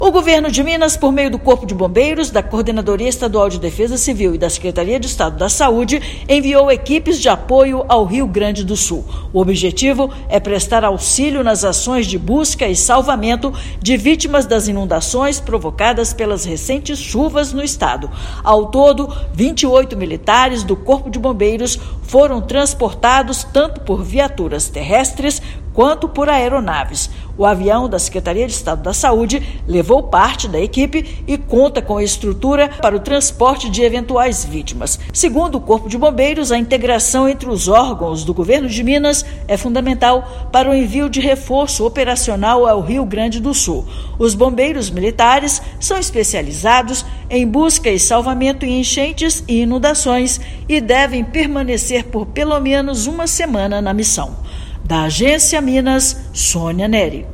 Ao todo, 28 bombeiros militares irão prestar apoio às cidades atingidas, com auxílio da Defesa Civil e da Secretaria de Estado de Saúde. Ouça matéria de rádio.